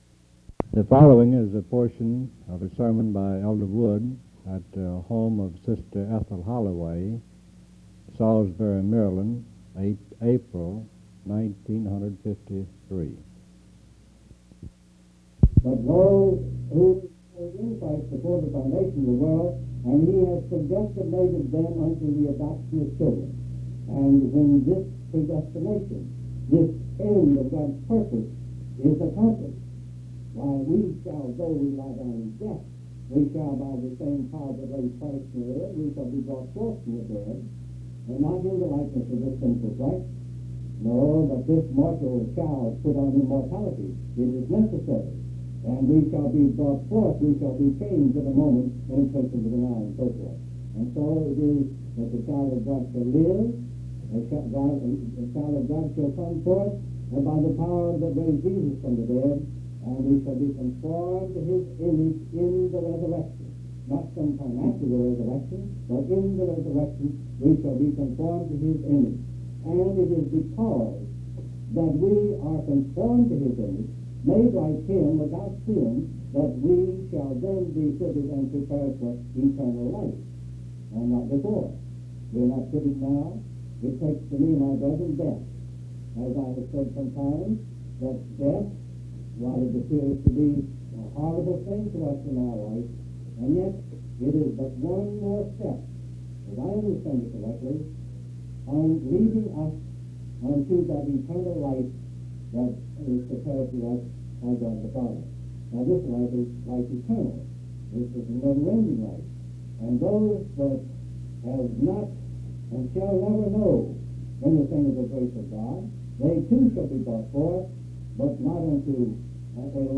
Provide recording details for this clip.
• Wicomico County (Md.)